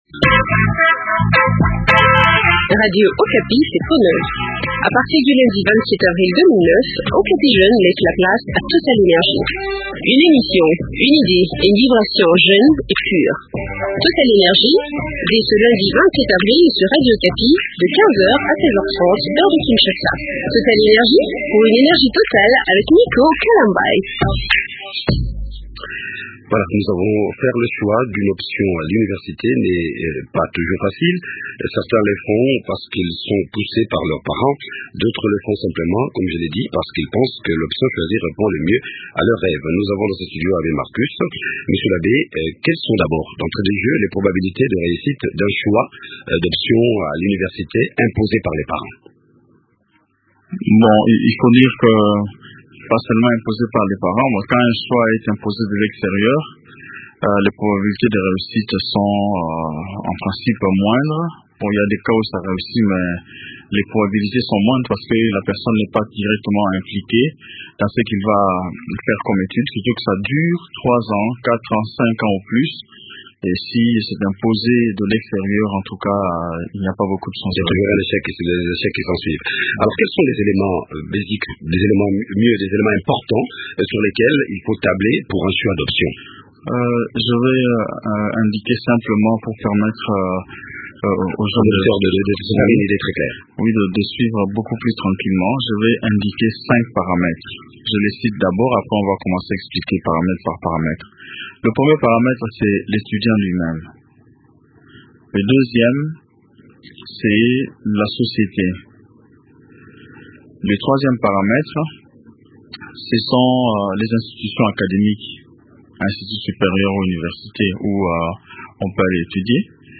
Sur quels critères doit-on se baser pour opérer un bon choix d’une filière de formation à l’université ? Eléments de réponse dans cet entretien